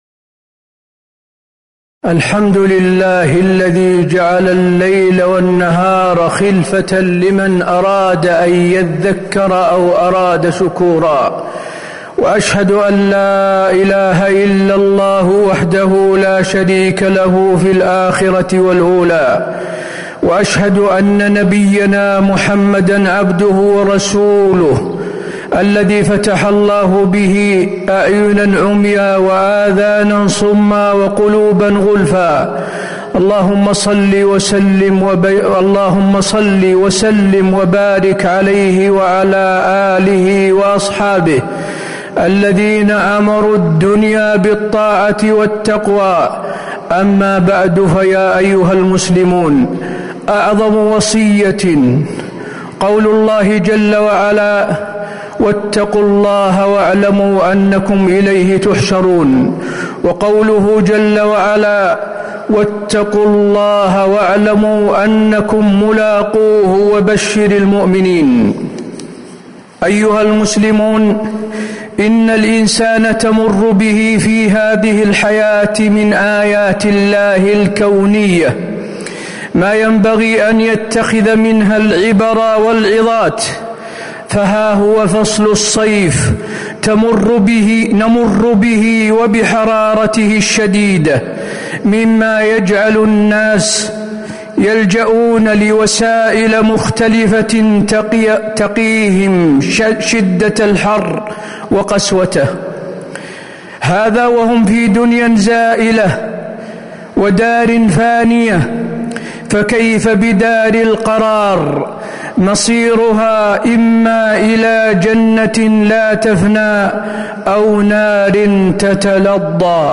تاريخ النشر ٢٧ محرم ١٤٤٦ هـ المكان: المسجد النبوي الشيخ: فضيلة الشيخ د. حسين بن عبدالعزيز آل الشيخ فضيلة الشيخ د. حسين بن عبدالعزيز آل الشيخ قل نار جهنم أشد حراً لو كانوا يفقهون